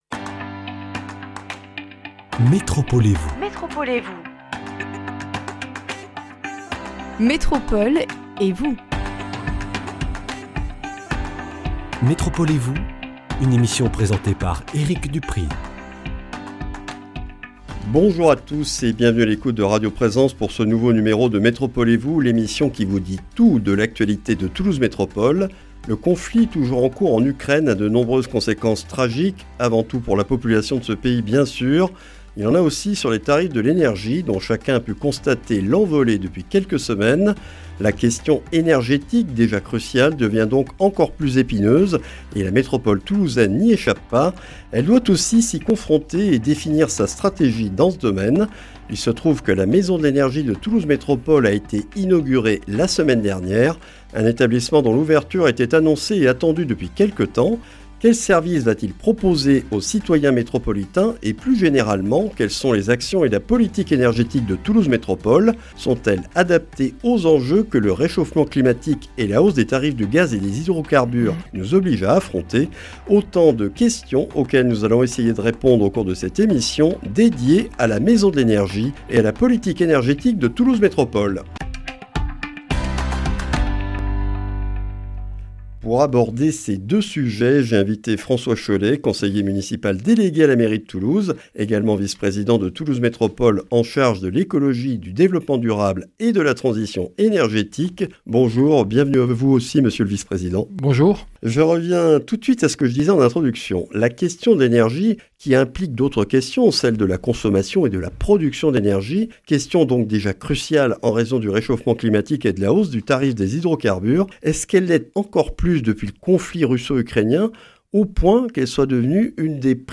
La Maison de l’Energie a été inaugurée le 6 avril. L’occasion de découvrir cet établissement et ses services, tout en rappelant les axes de la politique énergétique de Toulouse Métropole avec notre invité, François Chollet, conseiller municipal délégué à la mairie de Toulouse, vice-président de Toulouse Métropole chargé de l’Ecologie, du Développement durable et de la Transition énergétique.